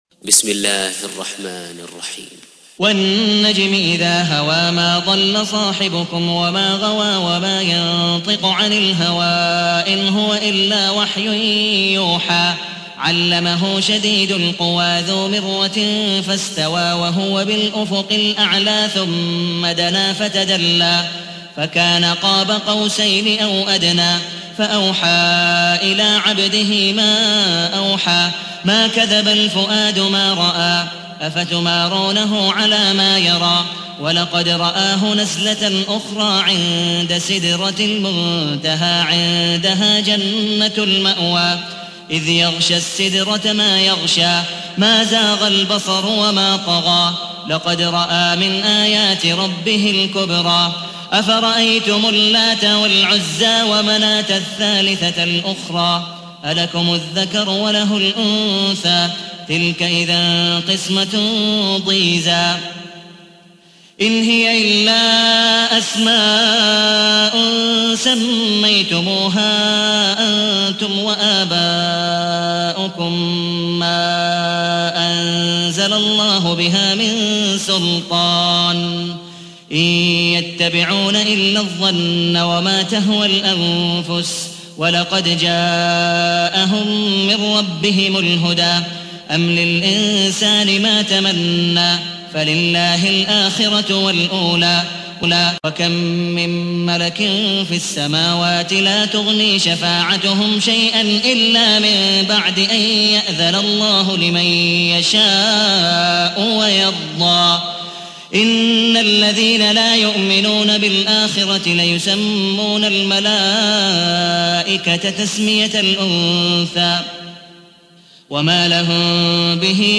تحميل : 53. سورة النجم / القارئ عبد الودود مقبول حنيف / القرآن الكريم / موقع يا حسين